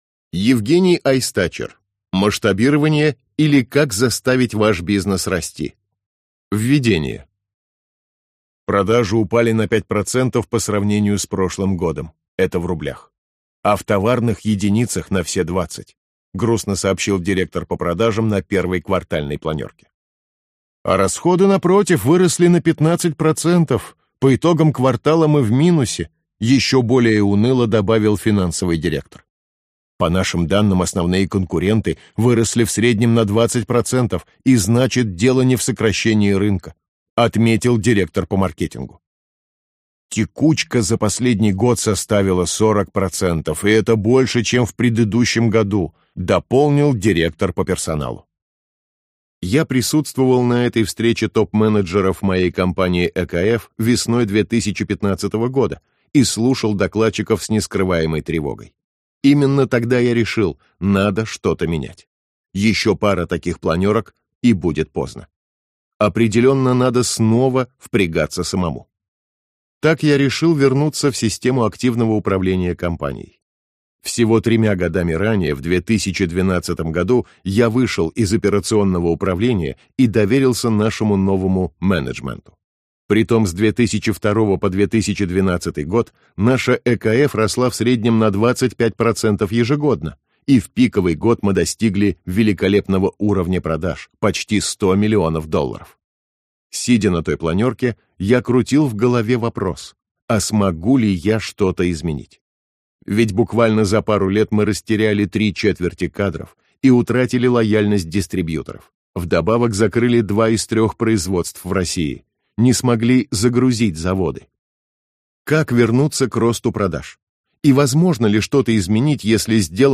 Аудиокнига Масштабирование, или Как заставить ваш бизнес расти | Библиотека аудиокниг